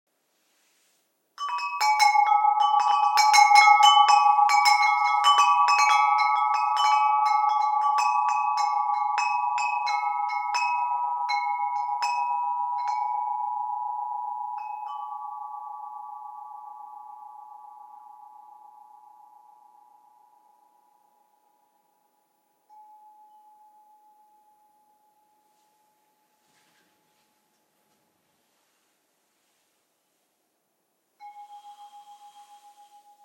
These chimes are characterized by a particularly good resonance and long reverberation. They are tuned on the base A4/a' 432 Hz. Enjoy the soft and soothing sound.
Control the sound by gently tilting the cord to the side to strike the Chimes.